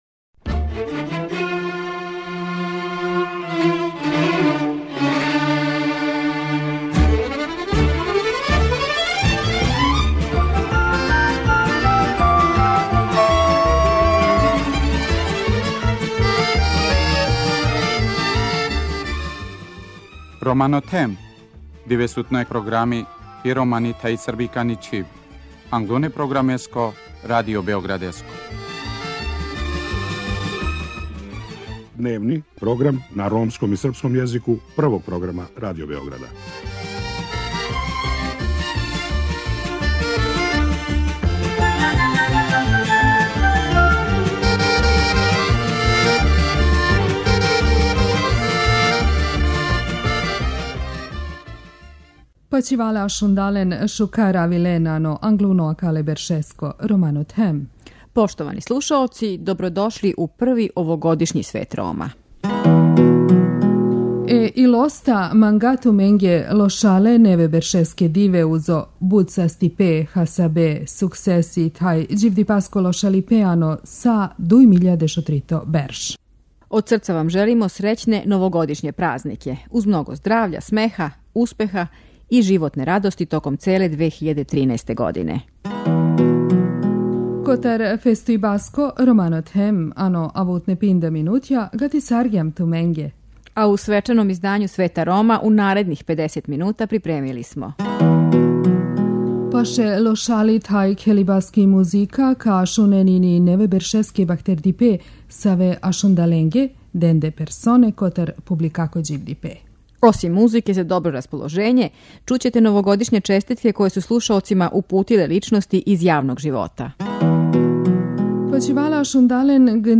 Због тога је, редакција Света Рома, свима онима који су вечерас с нама, припремила пуно добре музике, а чућете и новогодишње честитке које су вама и нама упутиле неке јако занимљиве личности из јавног живота.